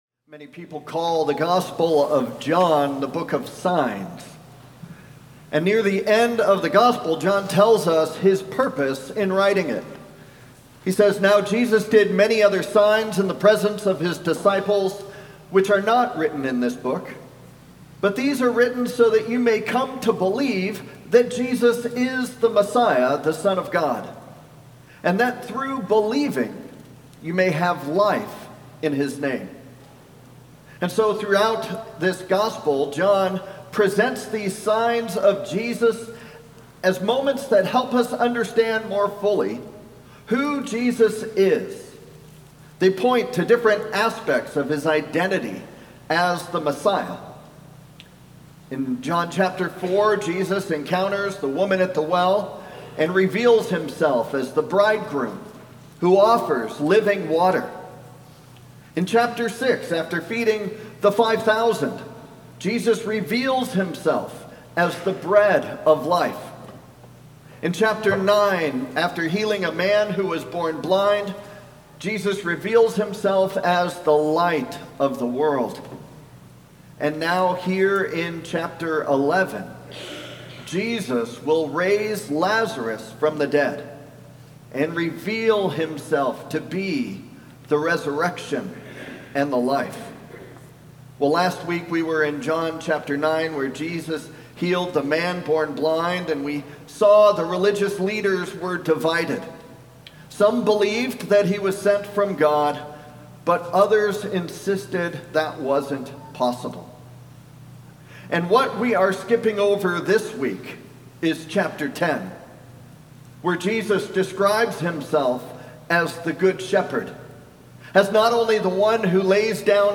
Sermon+3-22-26.mp3